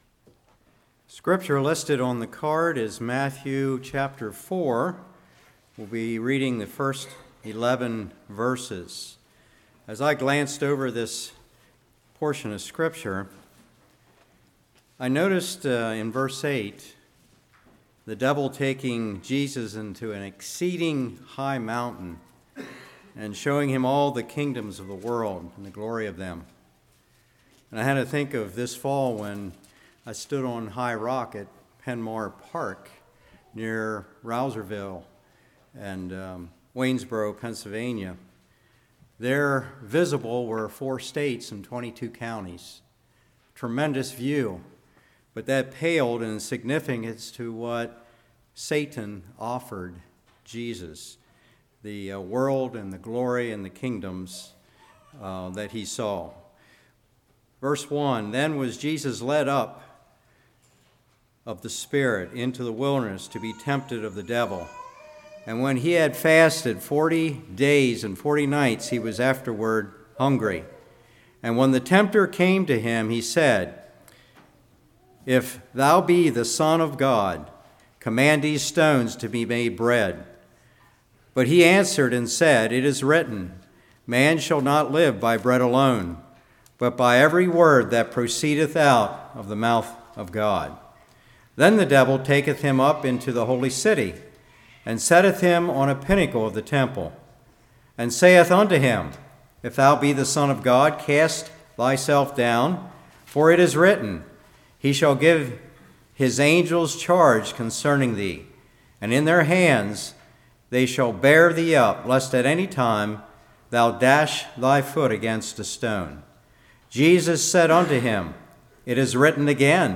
Matthew 4:1-11 Service Type: Revival Rebutting Temptation